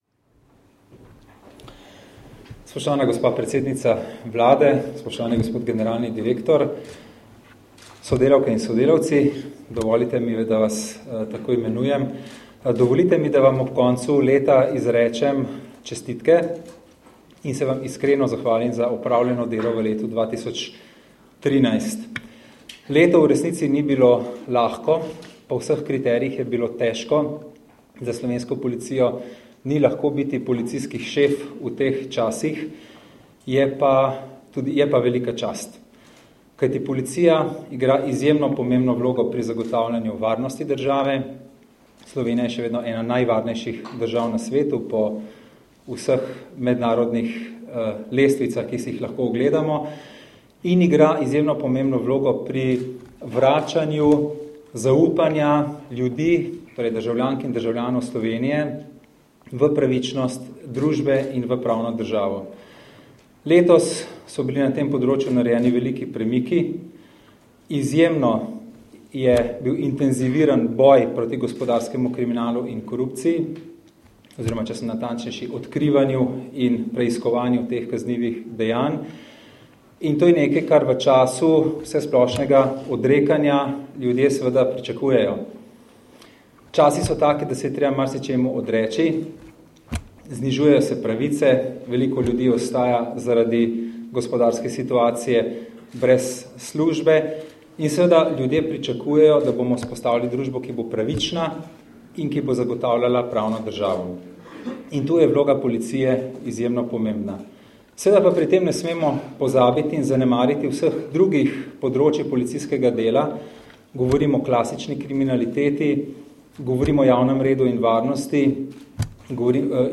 Zvočni posnetek nagovora ministra za notranje zadeve (mp3)